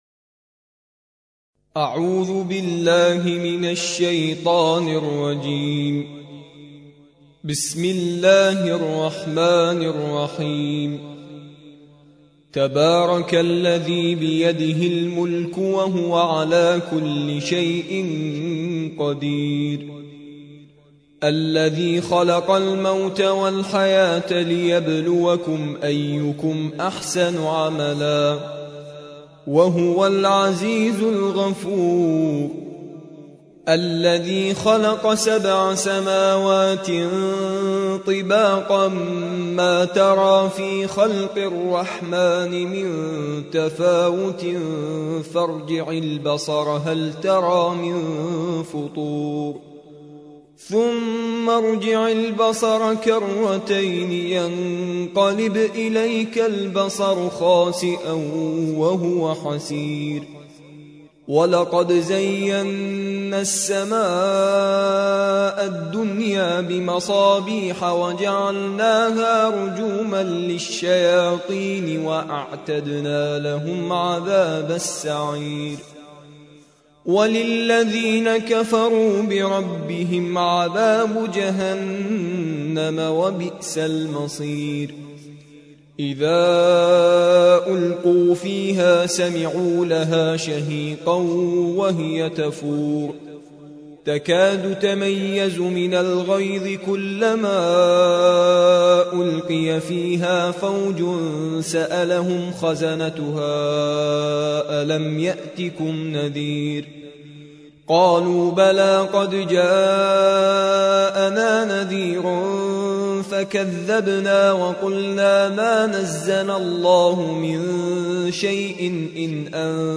67. سورة الملك / القارئ